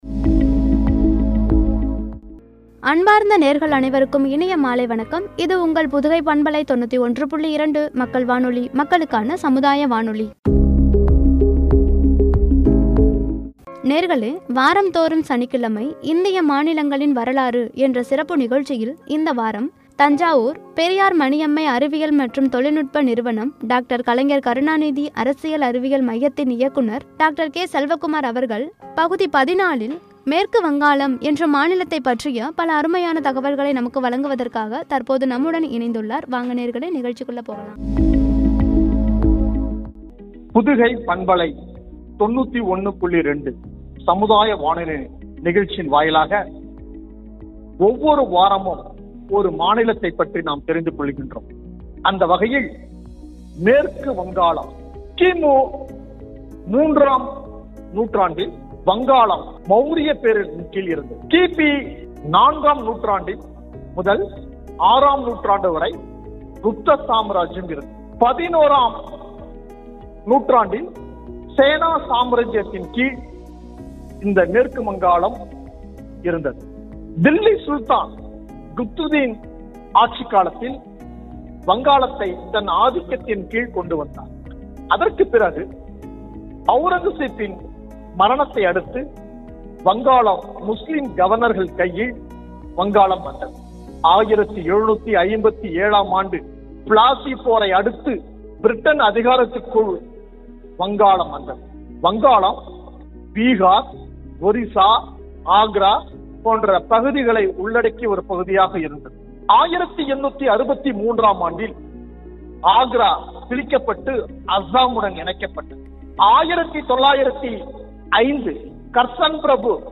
உரை